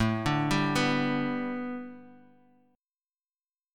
Listen to A9sus4 strummed